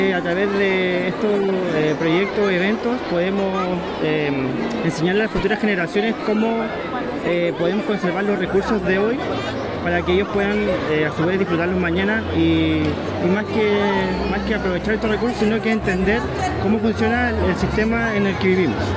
Testimonios